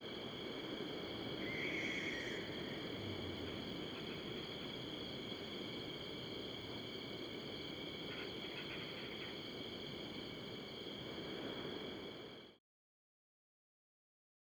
Cape Verde Barn Owl
A single, downward-inflected perennial screech.
1-24-Cape-Verde-Barn-Owl-Perennial-screech.wav